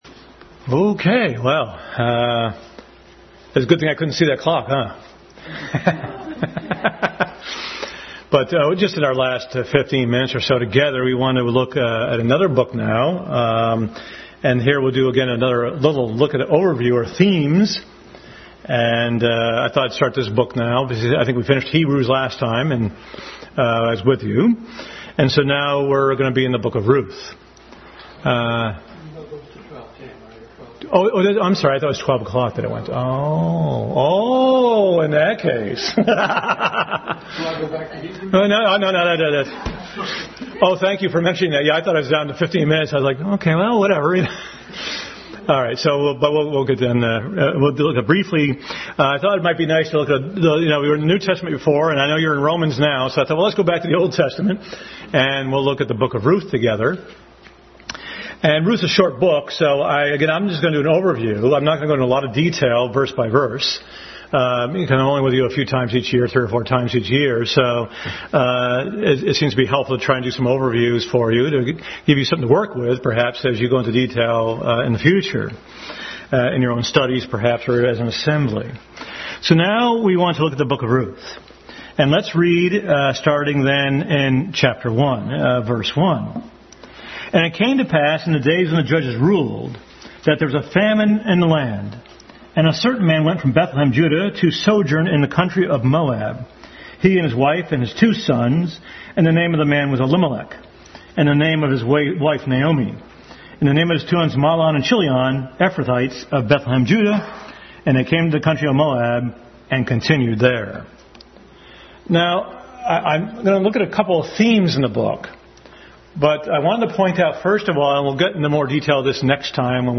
Deuteronomy 25 Service Type: Family Bible Hour Family Bible Hour beginning study in the book of Ruth.